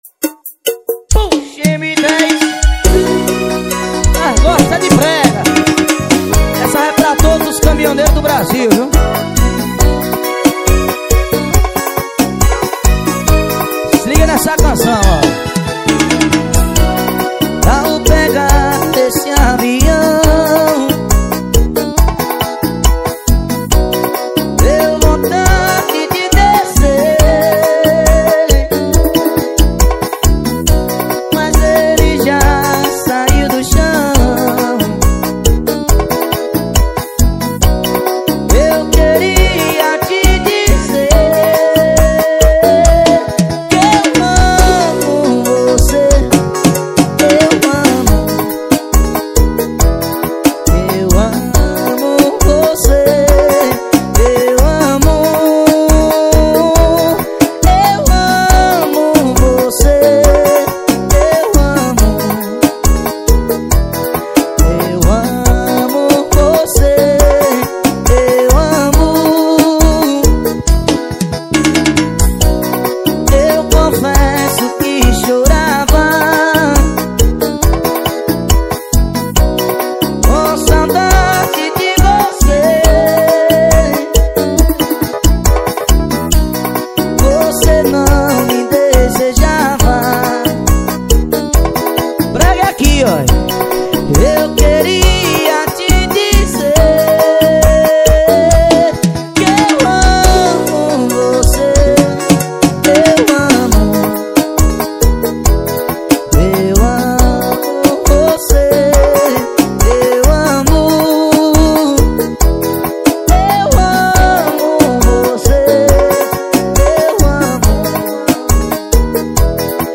2024-12-23 18:08:02 Gênero: Arrocha Views